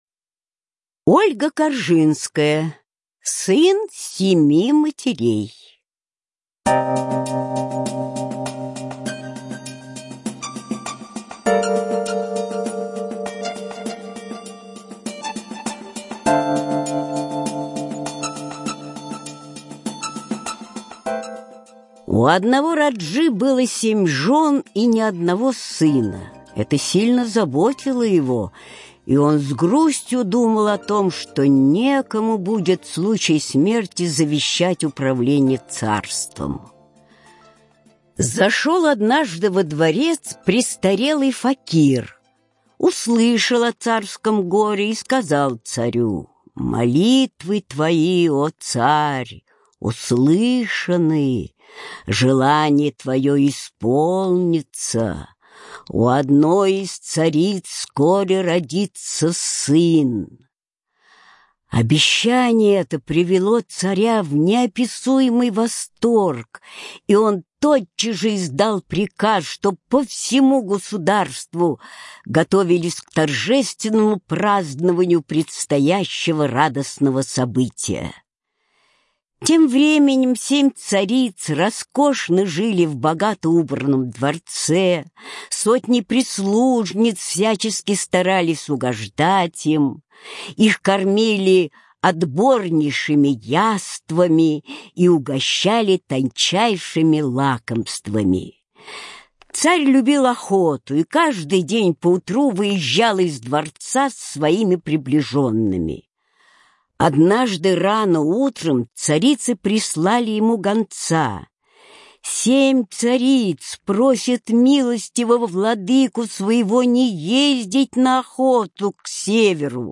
Сын семи матерей - аудиосказка Коржинской - слушать онлайн